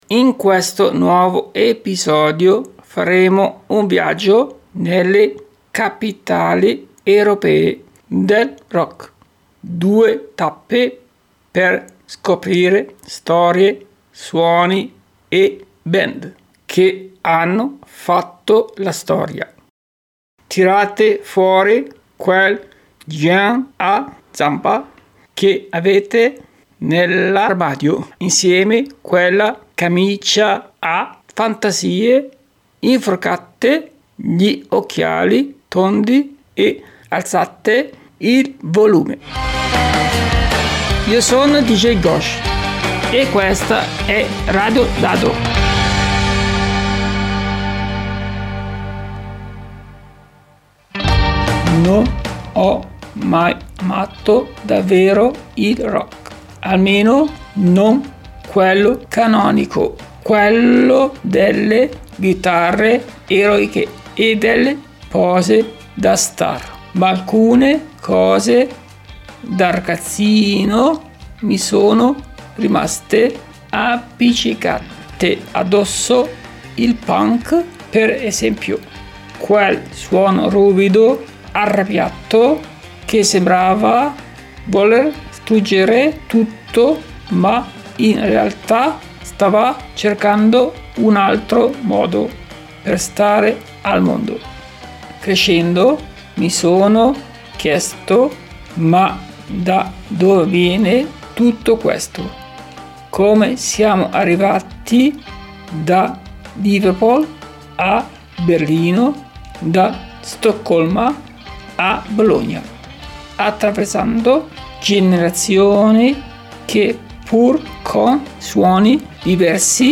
Radio Dado | Alle radici del rock europeo, tra chitarre ribelli, contaminazioni e nuove energie.
Un viaggio che parte dal beat e attraversa le rivoluzioni musicali che hanno cambiato il modo di vivere la musica… fino ad arrivare al punk, voce ruvida e urgente di una generazione.